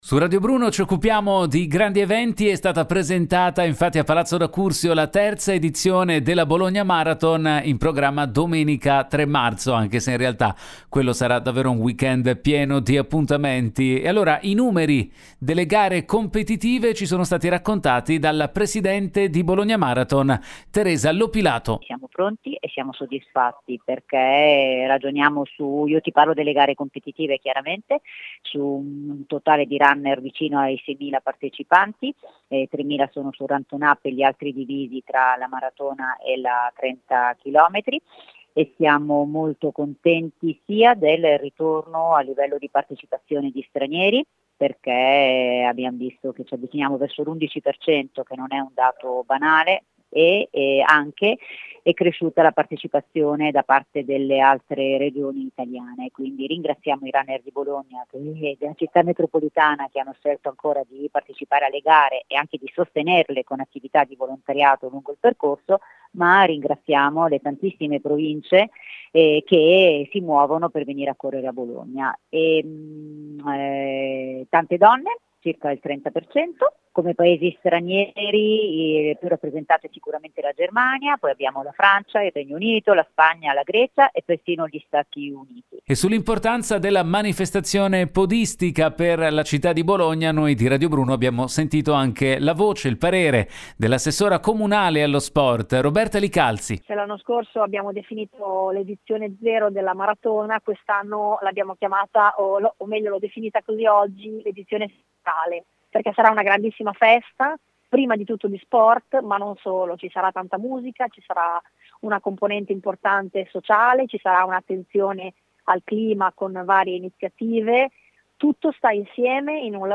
Home Magazine Interviste Manca pochissimo alla Bologna Marathon: i numeri dell’evento